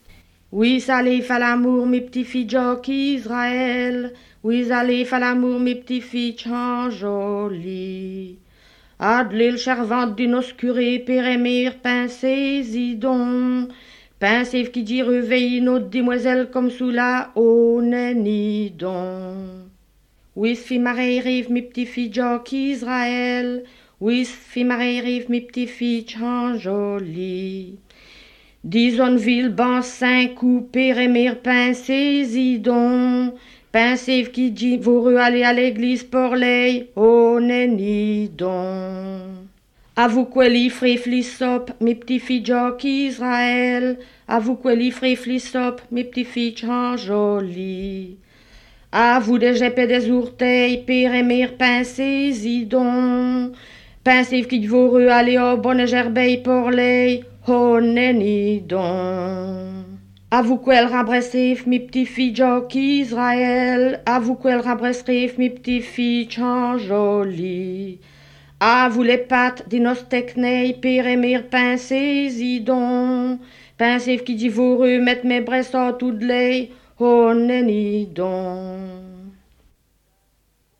Genre : chant
Type : chanson calendaire
Lieu d'enregistrement : Tilff (Esneux)
Support : bande magnétique